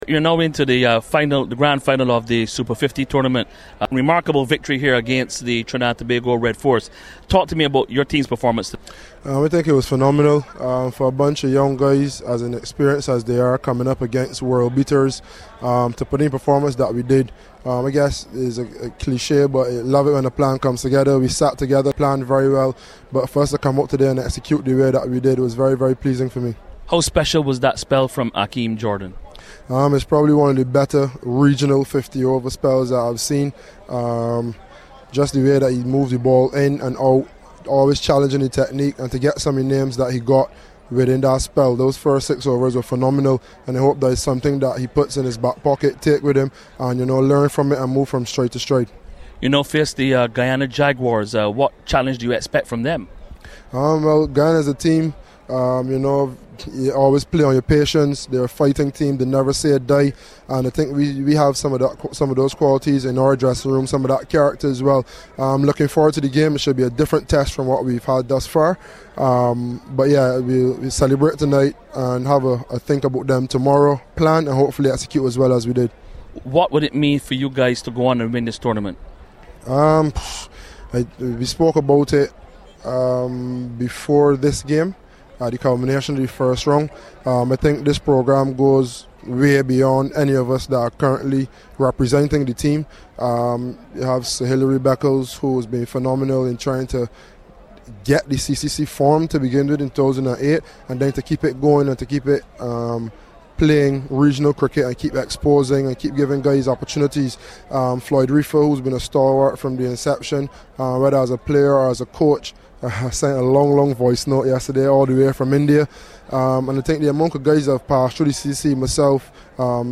Marooners captain Carlos Brathwaite spoke to CWI Media ahead of the showpiece Grand Final of the 2018-19 Super50 Cup on Sunday at Kensington Oval here.